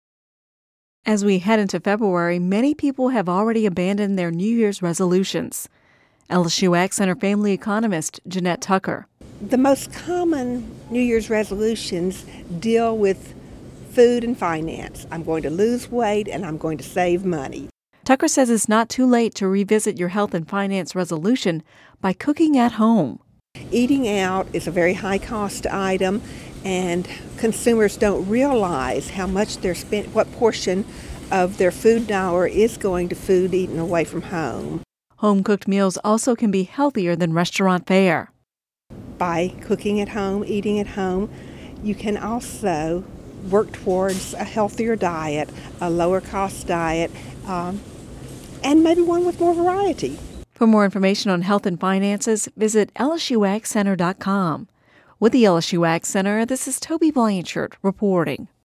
(Radio News 01/31/11) As we head into February, many people already have abandoned some or all of their New Year’s resolutions.